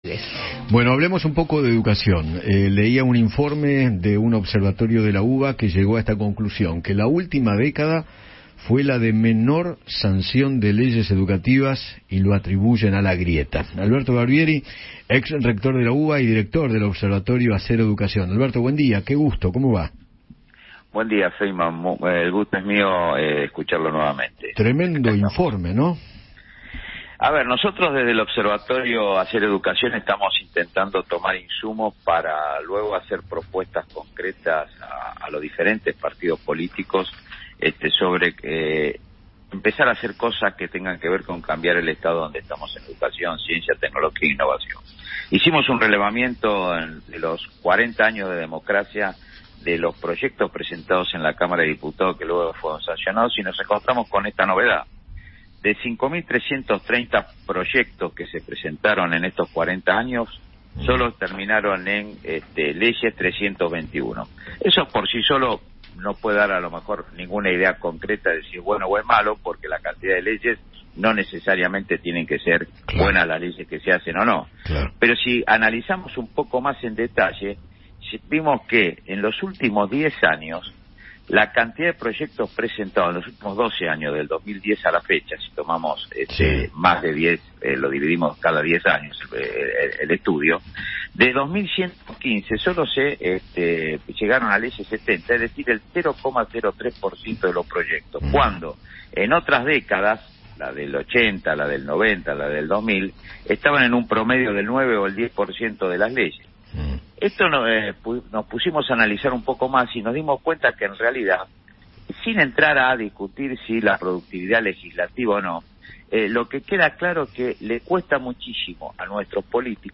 conversó con Eduardo Feinmann sobre el informe que realizó el observatorio “Hacer Educación” de la UBA